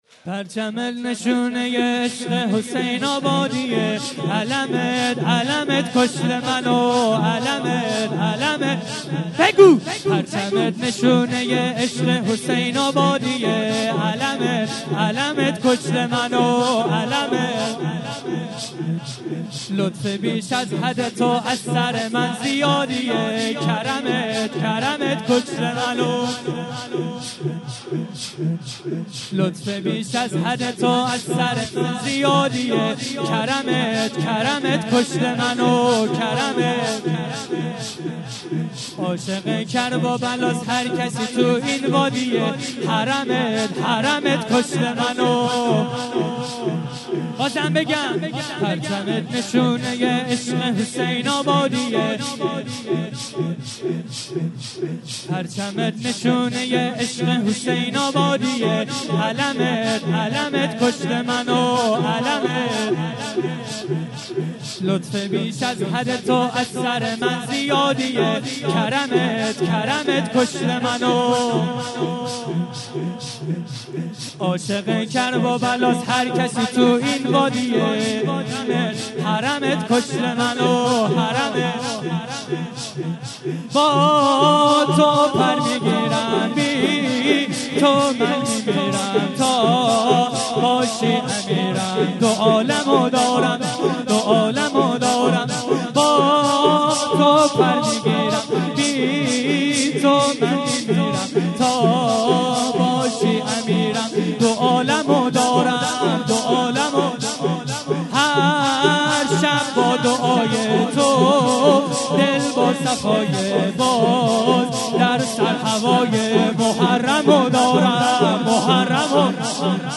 3- پرچمت نشونه عشق حسین آبادیه - شور